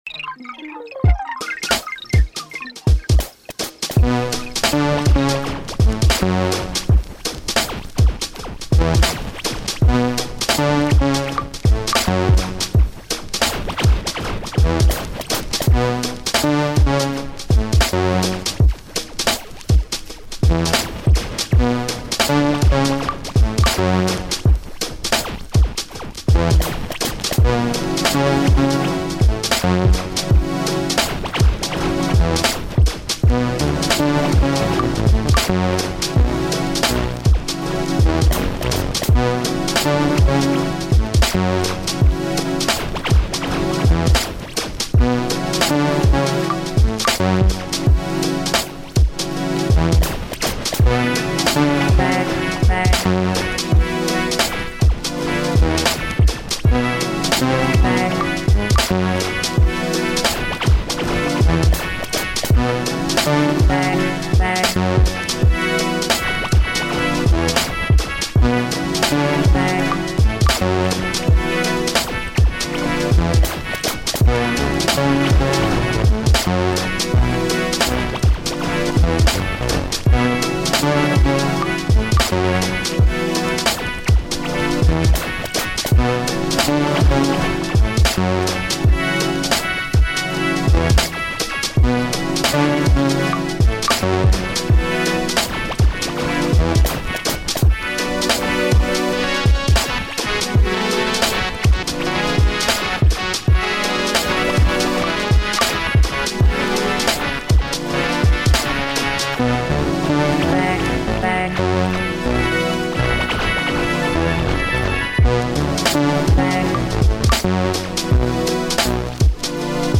beat tape